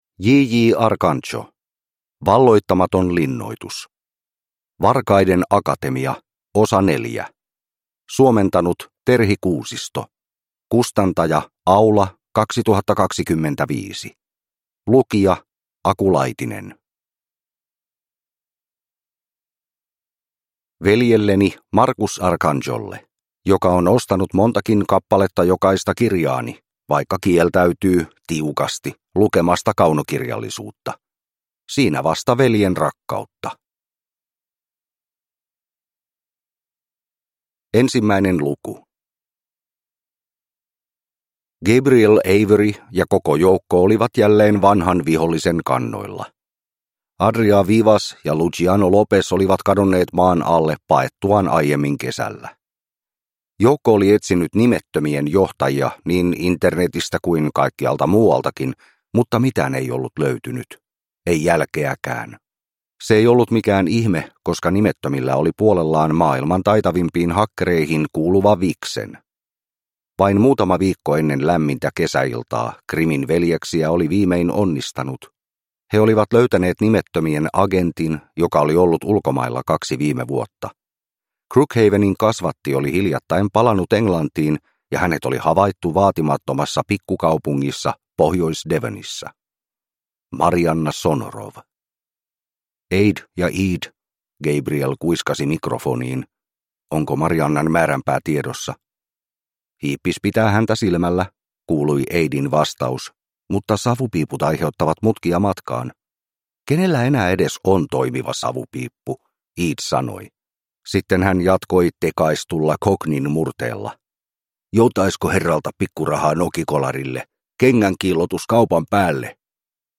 Valloittamaton linnoitus (ljudbok) av J. J. Arcanjo